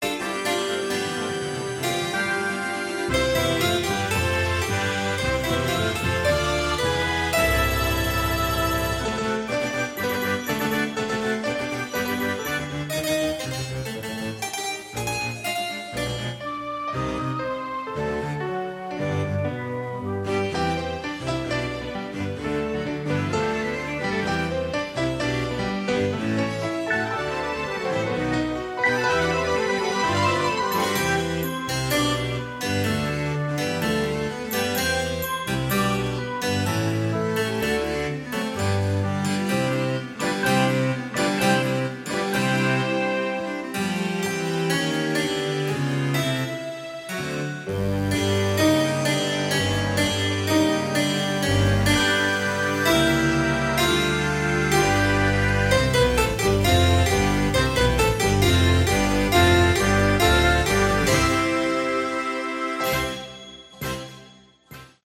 Category: Prog Rock
vocals
guitars
bass
drums
keyboards